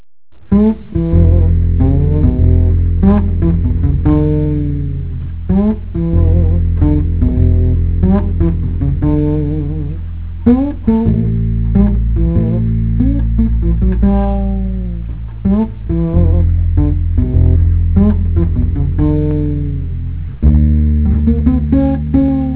Bass in Jazz